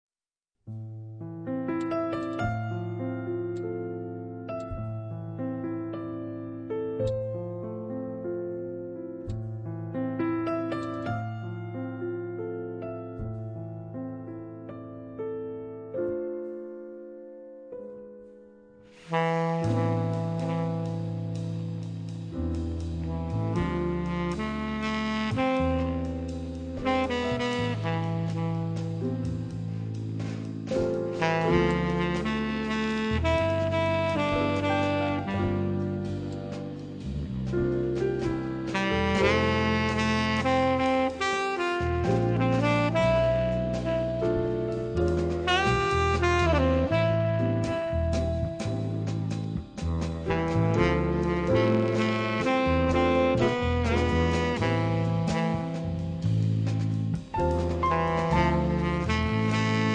sax tenore
pianoforte
basso
batteria